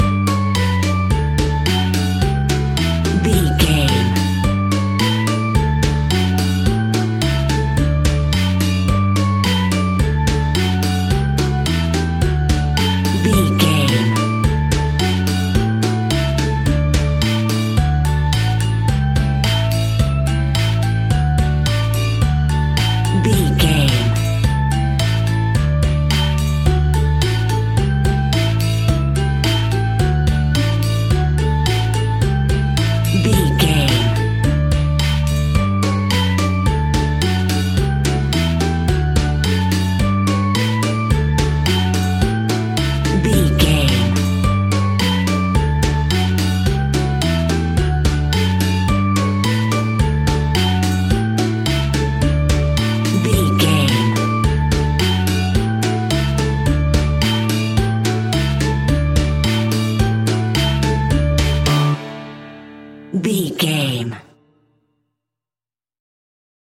Ionian/Major
D
Fast
fun
childlike
cute
kids piano